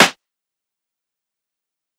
Snare (Pursuit of Happiness).wav